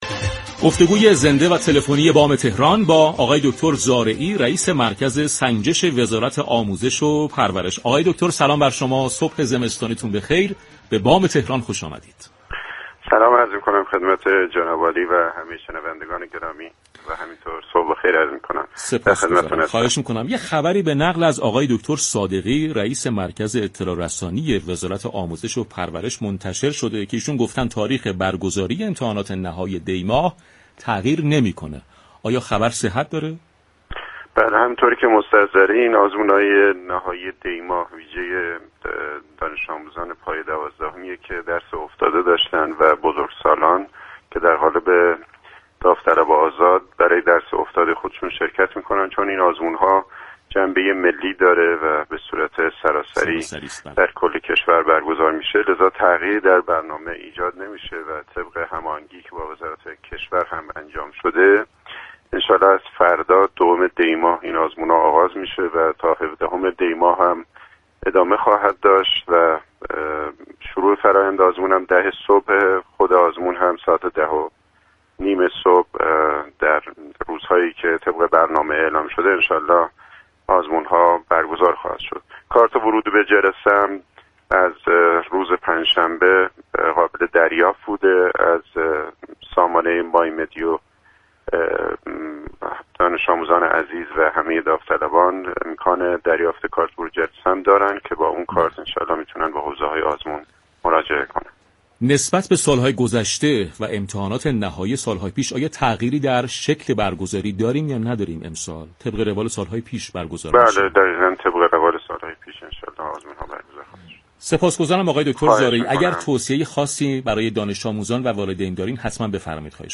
به گزارش پایگاه اطلاع رسانی رادیو تهران، محسن زارعی رئیس مركز سنجش وزارت آموزش و پرورش در گفت و گو با «بام تهران» اظهار داشت: امتحانات نهایی ویژه دانش‌آموزان غیرفارغ‌التحصیل پایه دوازدهم، بزرگسالان و داوطلبان آزاد تحت هیچ شرایطی لغو یا جا به جا نمی‌شود و از روز یكشنبه دوم دی شروع و تا 17 دی ادامه خواهد داشت. وی افزود: این آزمون جنبه ملی دارد و به صورت سراسری در كشور برگزار می‌شود.